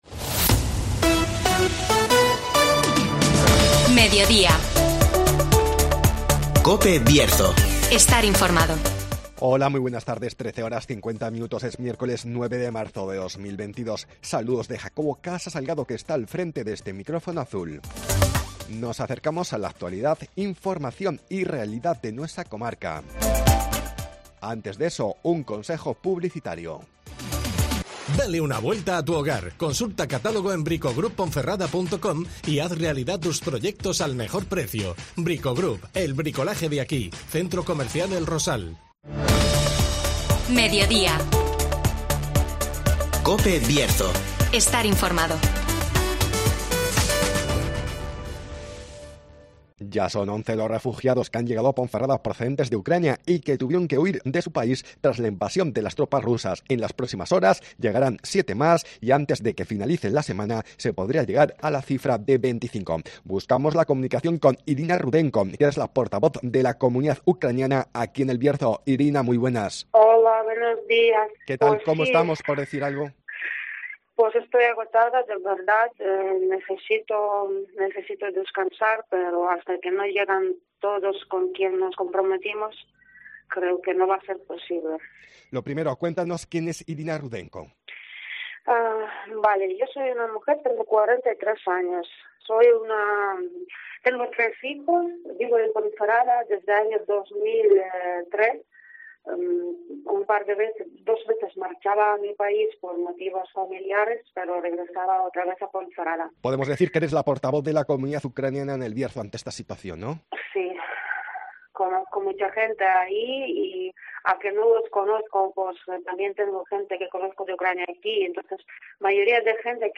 Ya son once los refugiados que han llegado a Ponferrada procedentes de Ucrania (Entrevista